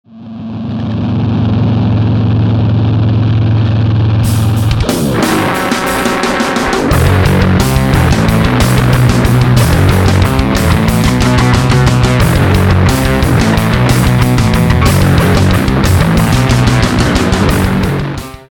Отдаю в хорошие музыкальные руки РИФФФФ  :selfmade2:  :selfmade:  :new_russian: